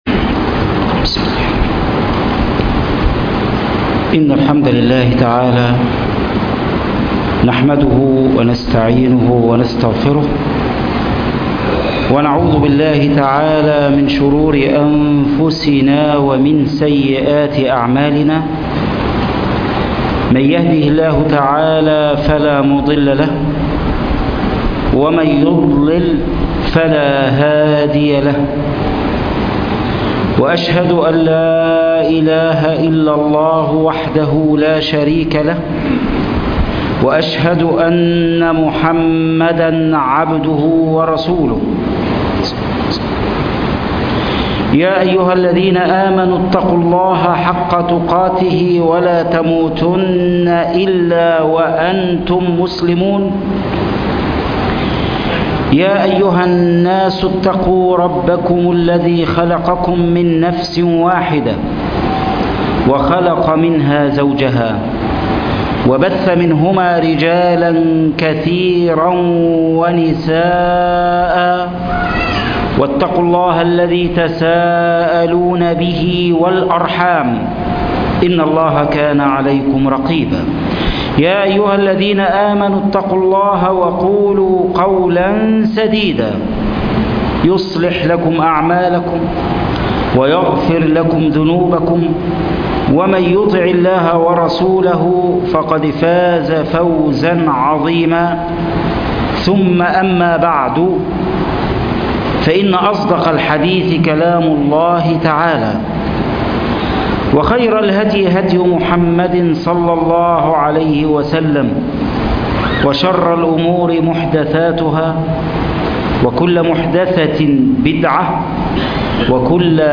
يدعون إلى الخير ( خطب الجمعة